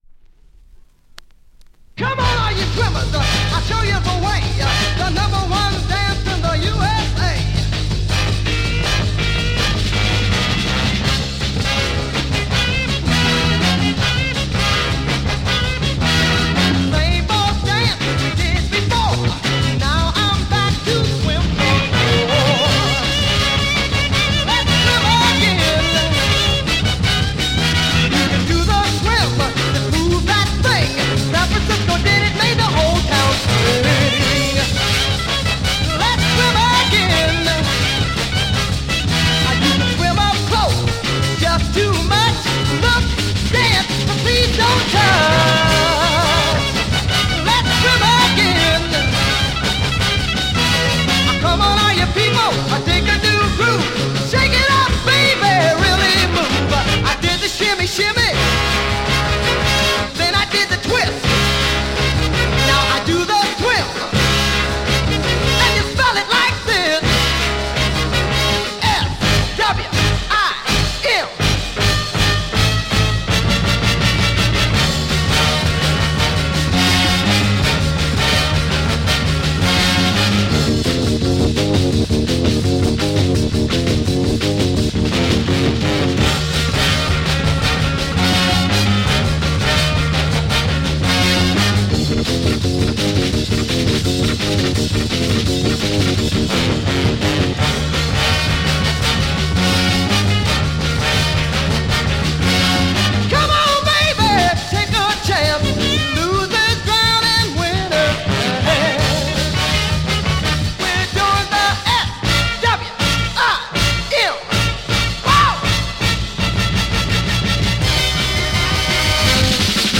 Killer R&B Soul smasher Mod EP!
Mega rare French EP issue, Killer R&B Mod smasher!!